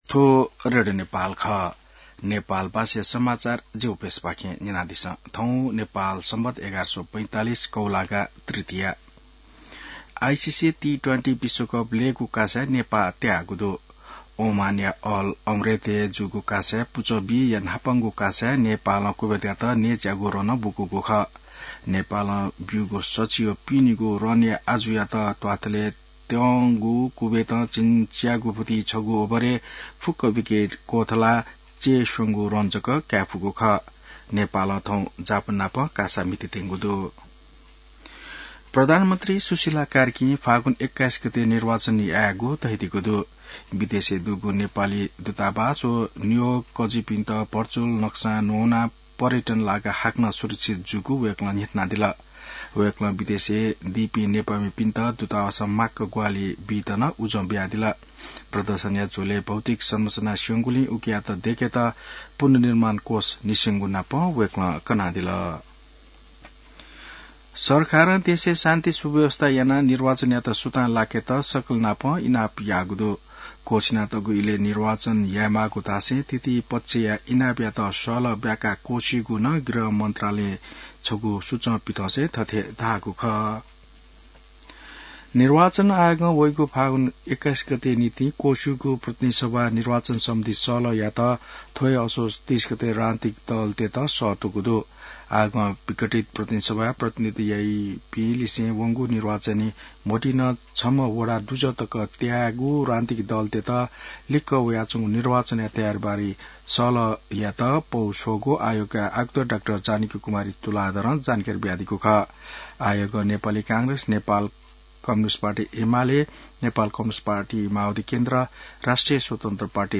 नेपाल भाषामा समाचार : २३ असोज , २०८२